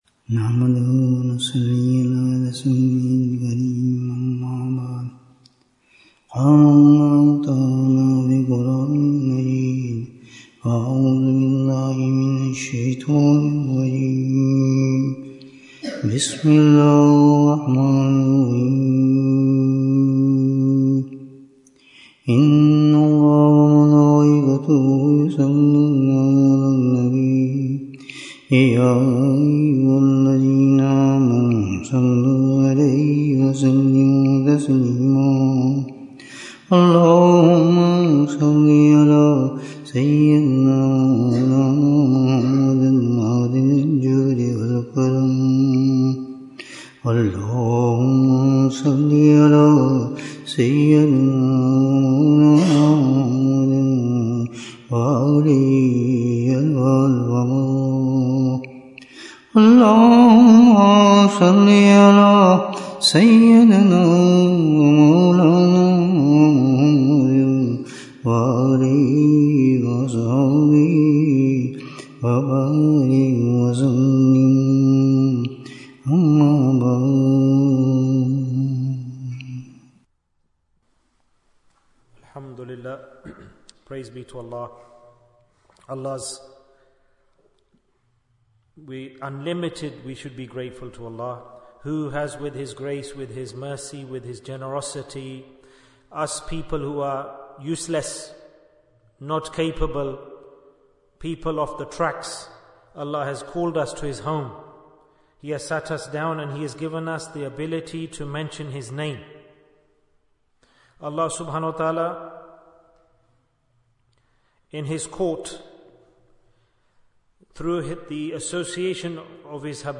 How do we Please Allah Ta'ala? Bayan, 68 minutes17th October, 2024